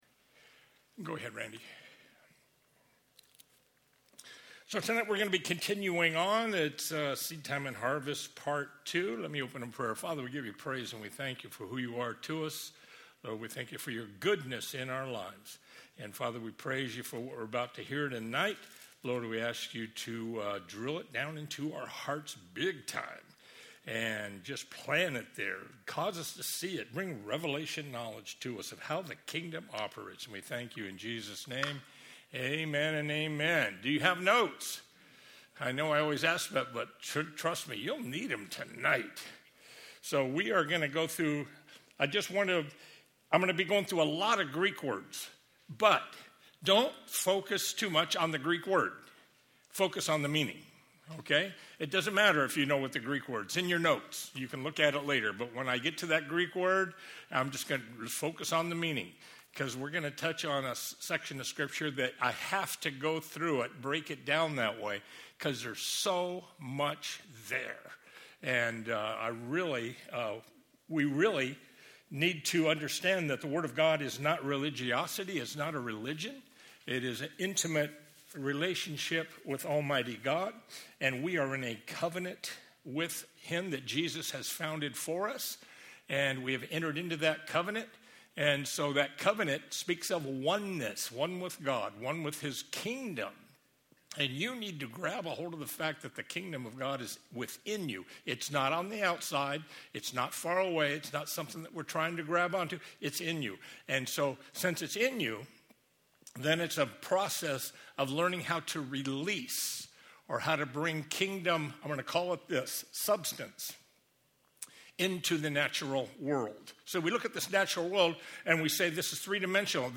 Sunday evening Bible study